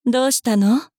大人女性│女魔導師│リアクションボイス
尋ねる